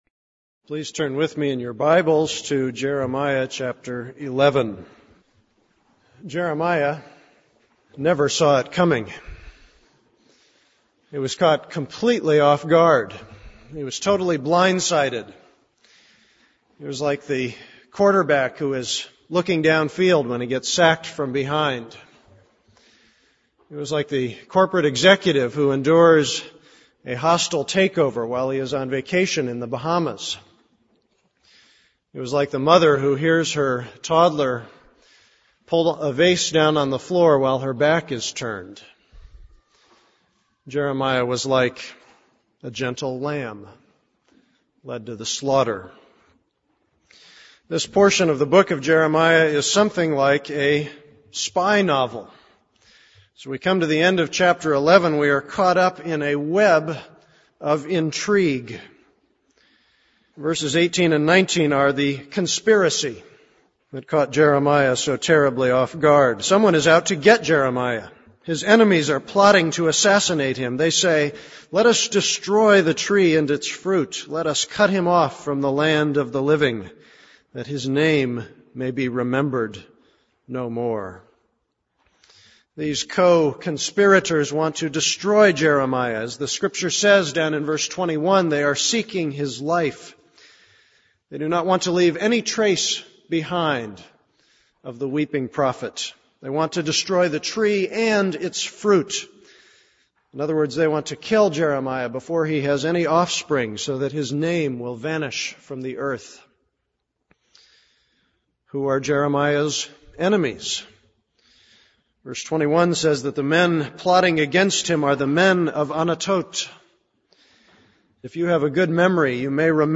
This is a sermon on Jeremiah 11:18-12:6.